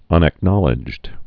(ŭnăk-nŏlĭjd)